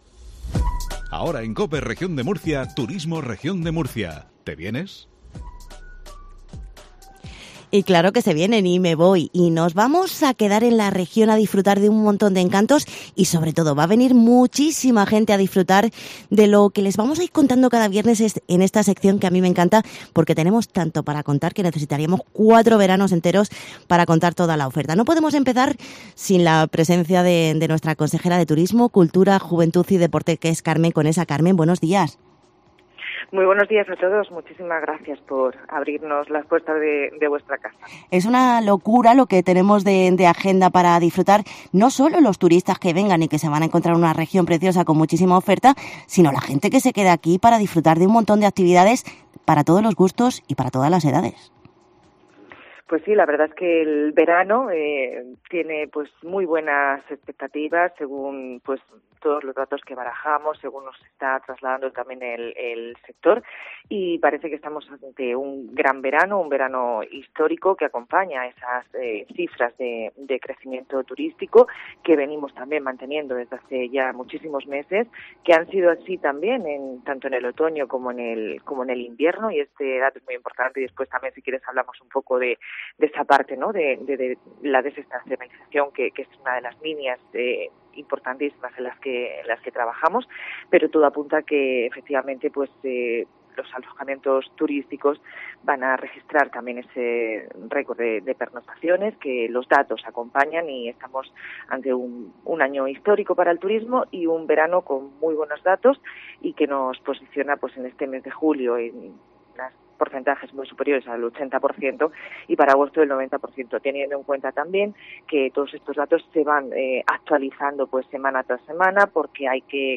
Carmen Conesa presenta en COPE Murcia la campaña 'Región de Murcia, Felicidad de la Buena'